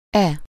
Ääntäminen
Synonyymit ifall såvida huruvida därest Ääntäminen Tuntematon aksentti: IPA: /ɔm/ Haettu sana löytyi näillä lähdekielillä: ruotsi Käännös Ääninäyte 1.